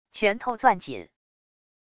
拳头攥紧wav音频